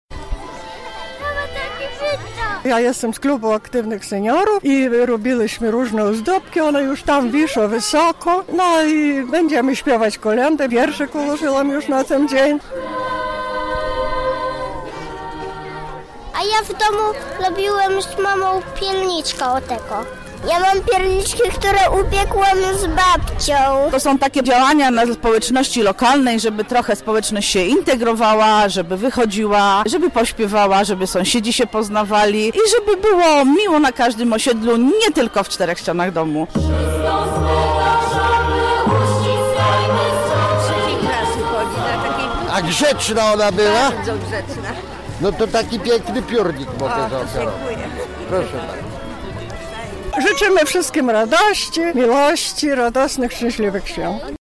Na placu przy fontannie zgromadziły się dzieci, dorośli oraz seniorzy. Wspólnie śpiewali kolędy oraz ozdabiali choinkę.